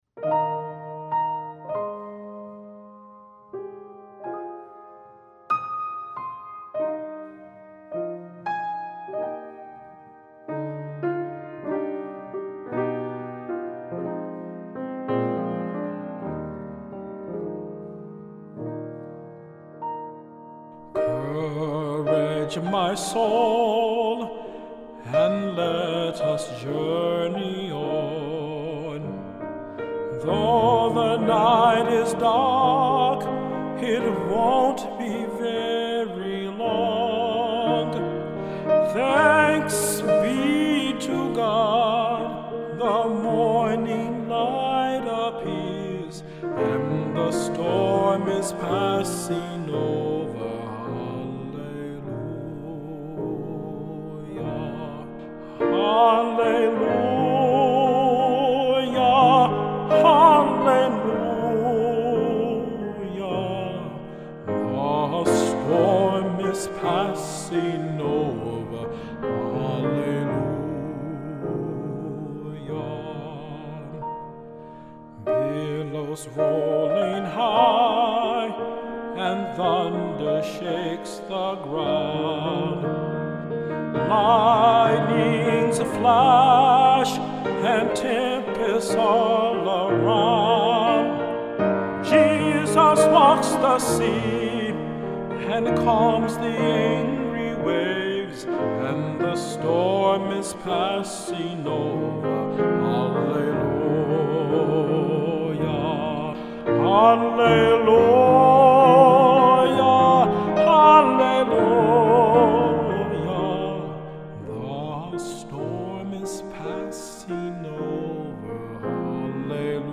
Most of the tunes for the film needed to be just piano, but he asked if I didn’t also want to record him singing, too.